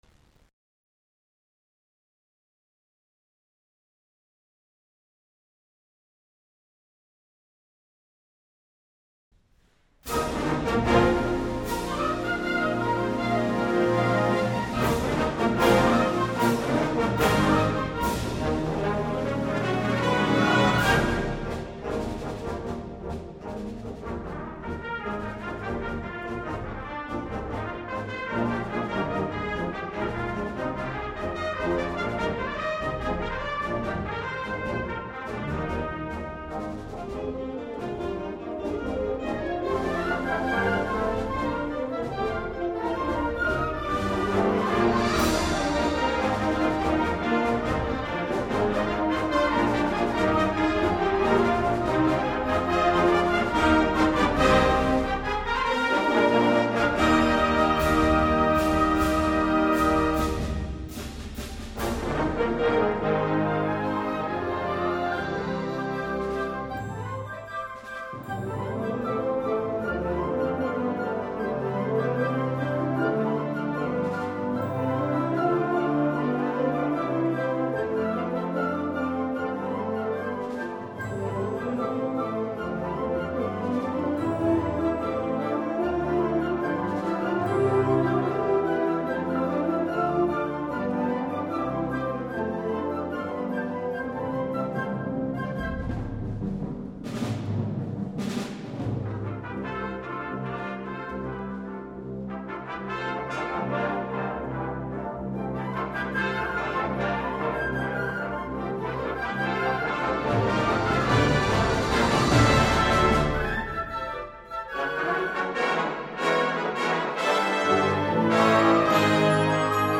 Genre: Band
the essence of joy and celebration from start to finish
Piccolo
Bassoon
F Horn 1, 2, 3, 4
Timpani
Percussion 1 [2 players] (snare drum, bass drum)